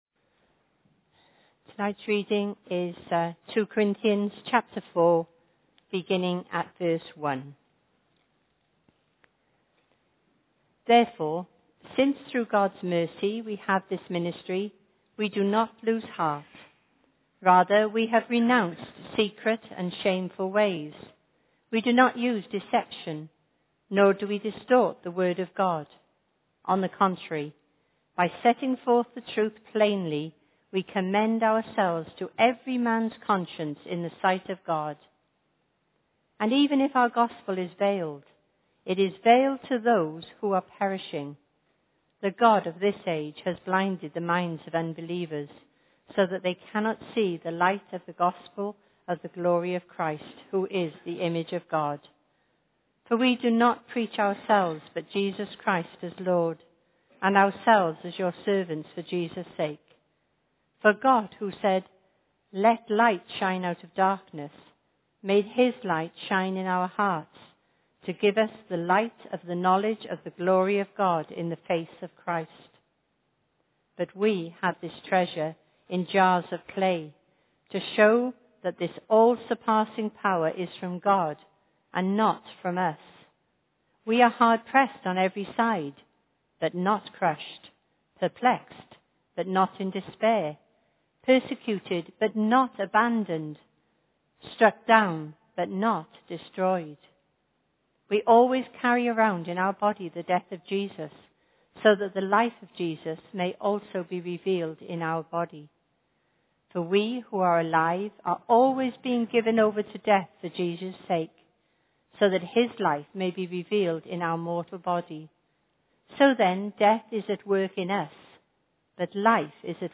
no. Genre: Speech.